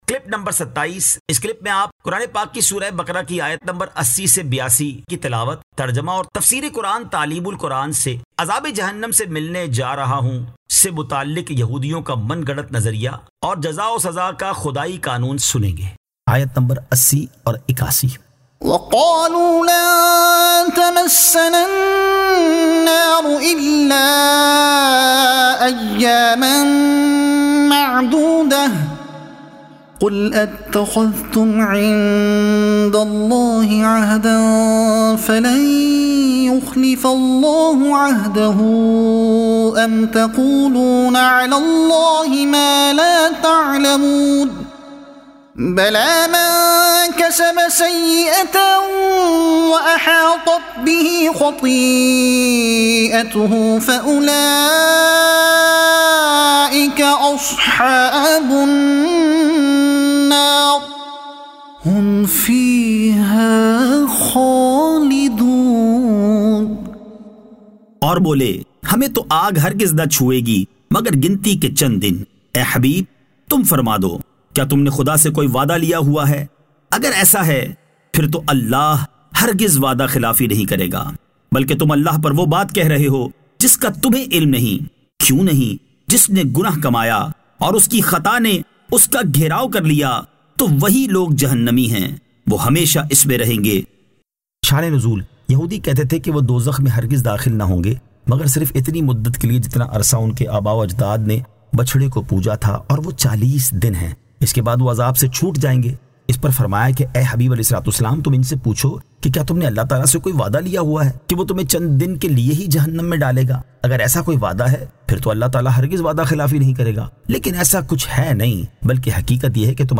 Surah Al-Baqara Ayat 80 To 82 Tilawat , Tarjuma , Tafseer e Taleem ul Quran